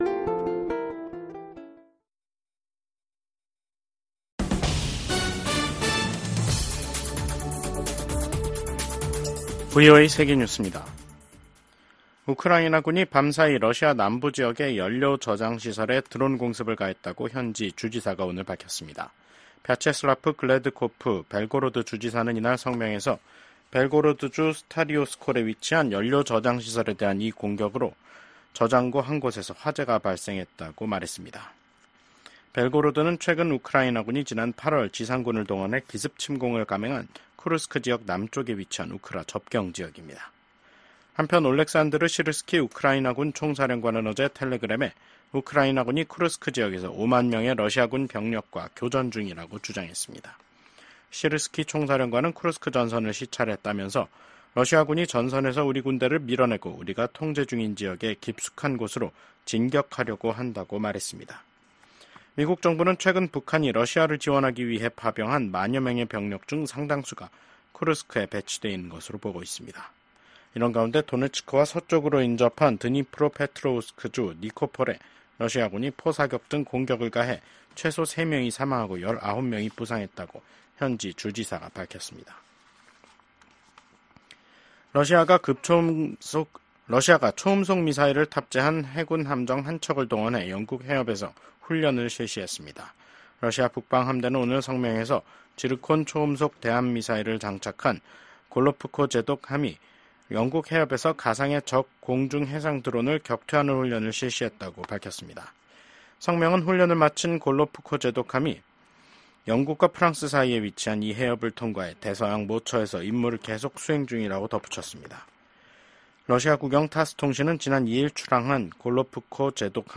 VOA 한국어 간판 뉴스 프로그램 '뉴스 투데이', 2024년 11월 12일 2부 방송입니다. 미국 국무부가 한국에 대한 확장억제 공약을 확인하며, 북한의 핵 공격 시 신속한 응징에 나설 것임을 강조했습니다. 김정은 북한 국무위원장이 러시아와의 관계를 군사동맹 수준으로 끌어올린 북러 조약에 서명하고 양국이 비준 절차를 마무리함에 따라 북한의 러시아 파병 등이 공식화, 본격화할 것이라는 전망이 나옵니다.